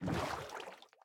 paddle_water6.ogg